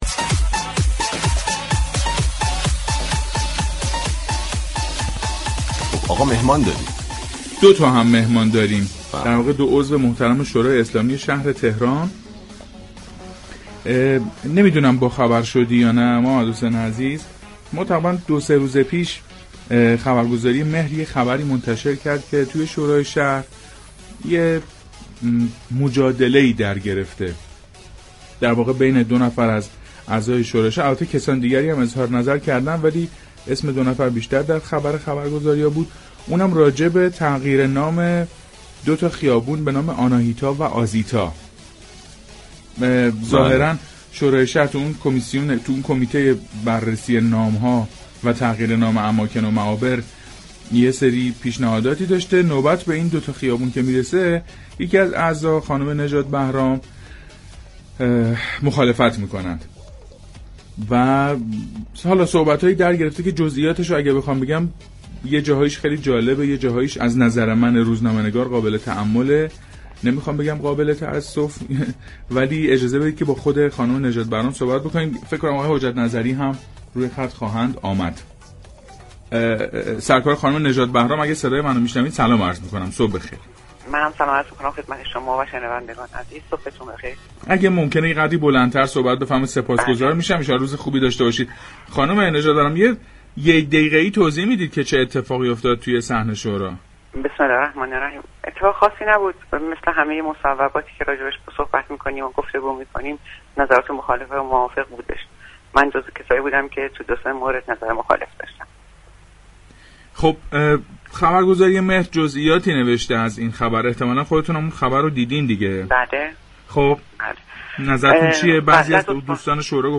به گزارش پایگاه اطلاع رسانی رادیو تهران، زهرا نژادبهرام عضو شورای شهر تهران در گفتگو با برنامه پارك شهر رادیو تهران گفت: تعداد معابری كه در سطح شهر به نام زنان است بسیار محدود است اجازه بدهیم این اسامی باقی بمانند و اگر می‌خواهیم نامگذاری و تغییر نام اماكن و معابر عمومی شهر تهران تغییر كند از معابری كه بی‌‌نام هستند و یا شماره‌گذاری شده اند استفاده كنیم.